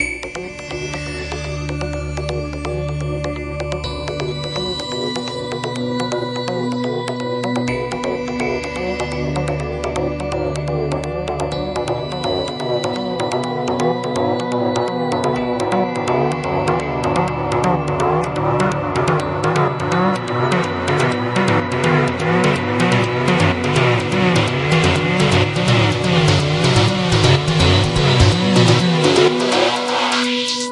空间/工业声音，建立或介绍..在Ableton制造